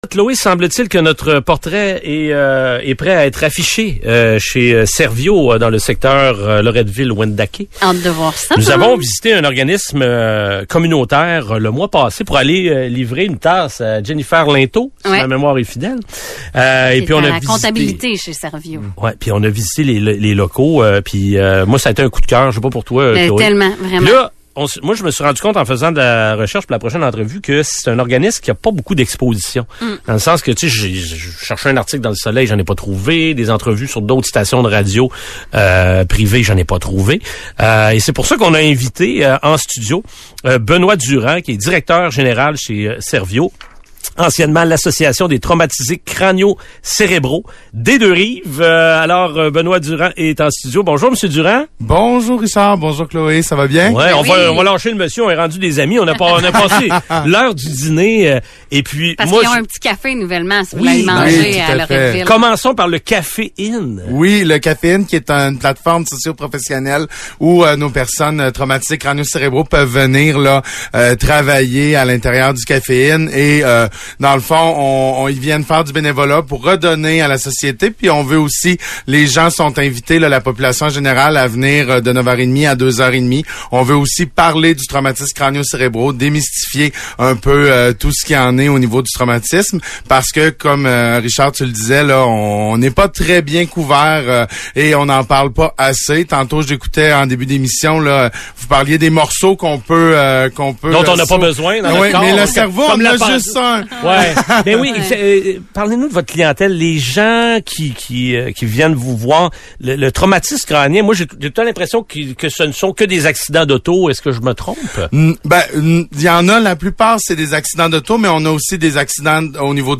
entrevue radio pour parler de ses services et des personnes qui fréquentent l'organisme ?